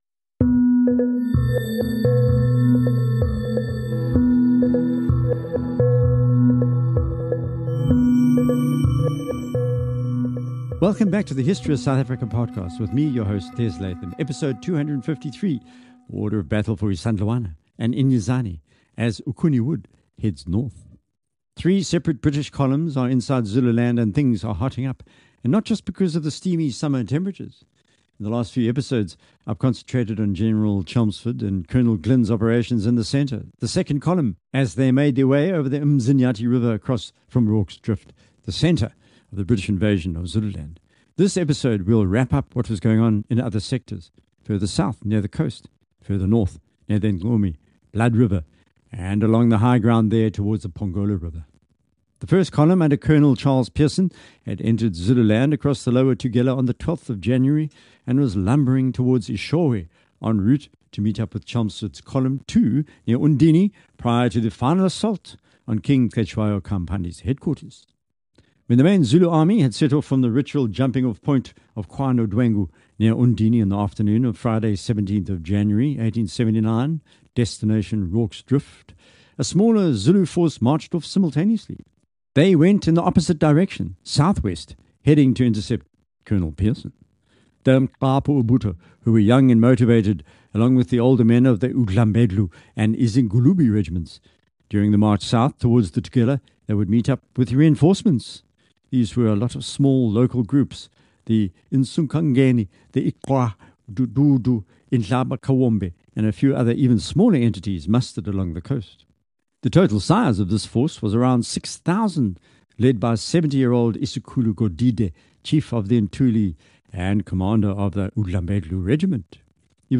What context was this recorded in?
1 Ep. 419 (Reply of the LIVE Postgame Show) Lions lose to the Steelers 28:10